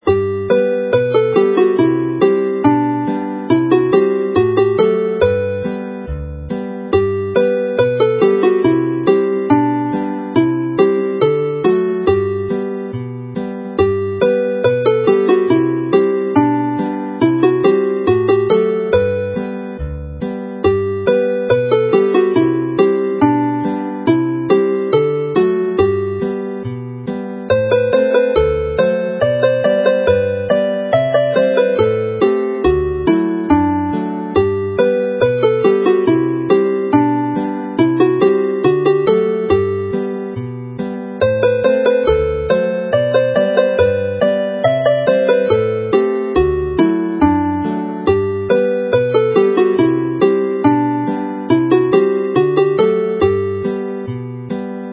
Play the melody slowly